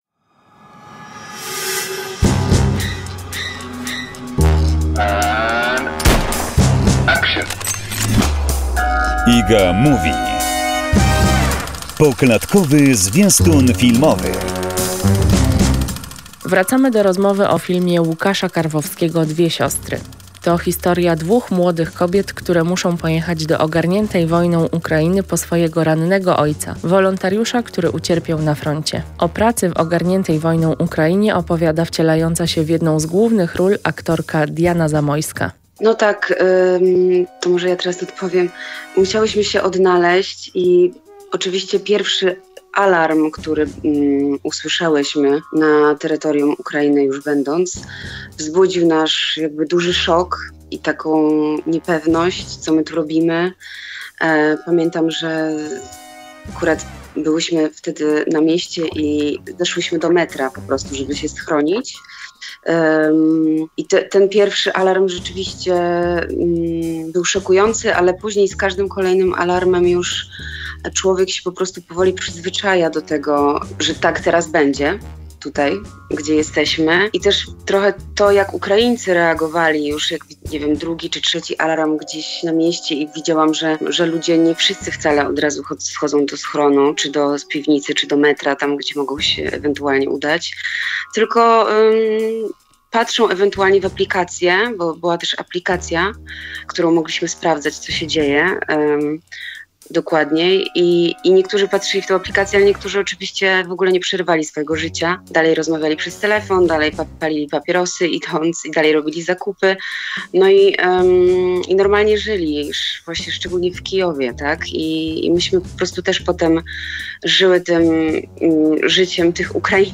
Wracamy do rozmowy o filmie Łukasza Karwowskiego "Dwie siostry".